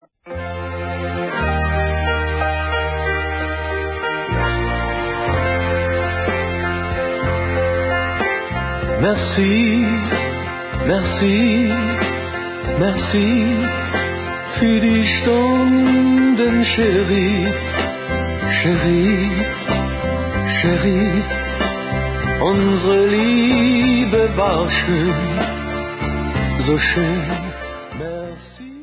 Umgangsmusik